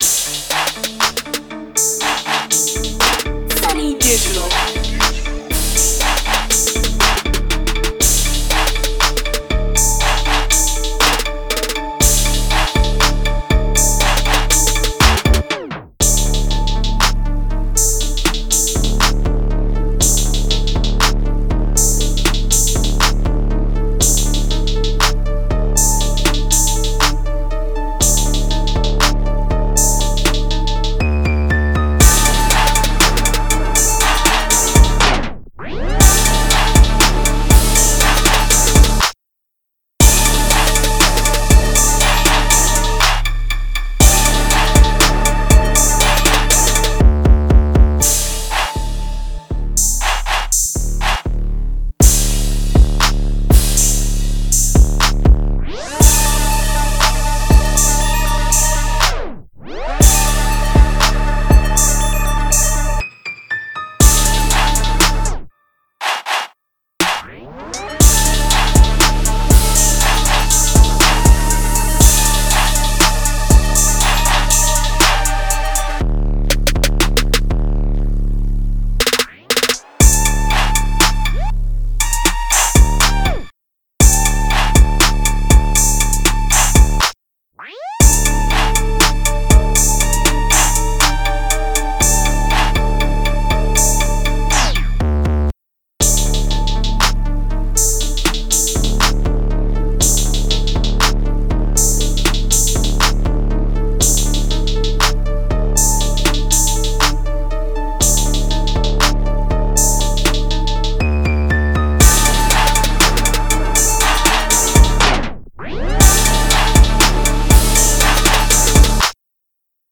THIS RECORD IS STRONG, POWERFUL, AND A DEFINITE BANGER.